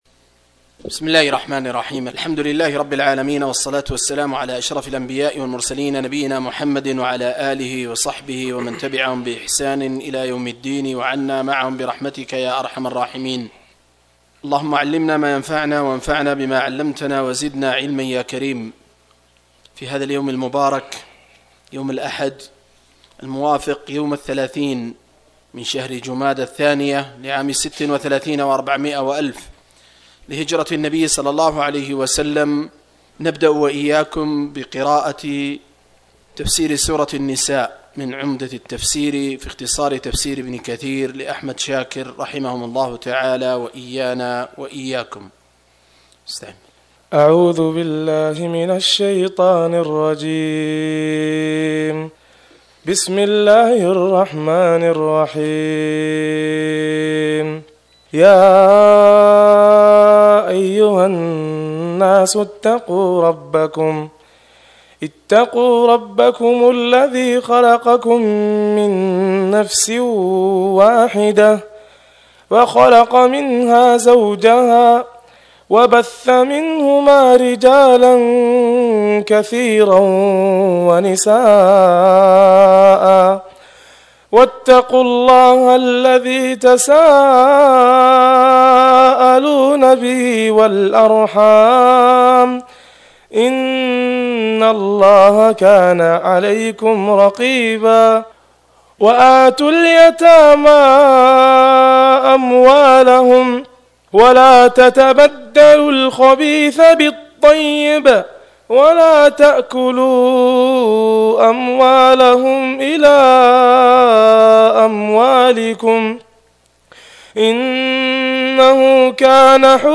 081- عمدة التفسير عن الحافظ ابن كثير رحمه الله للعلامة أحمد شاكر رحمه الله – قراءة وتعليق –